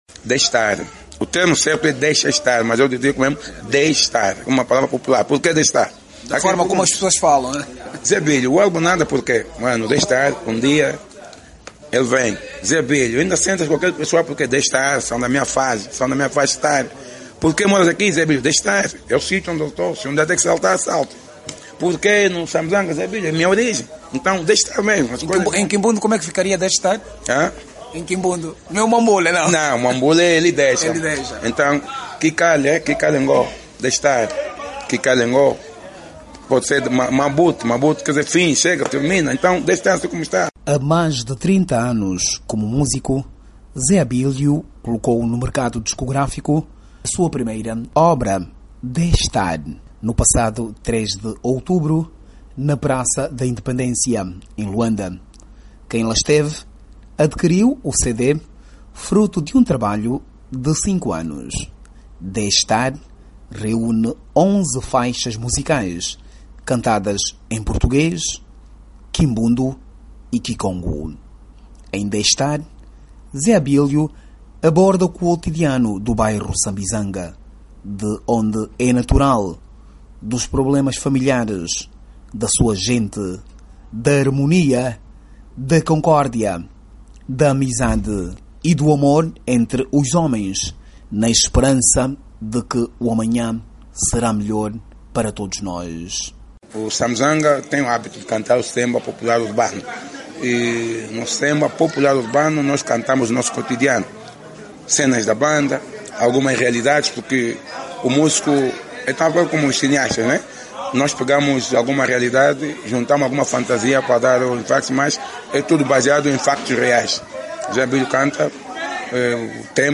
Semba, rebita, kilapanga, moda cabecinha e bolero são os géneres cantados em quimbundo, kicongo e português.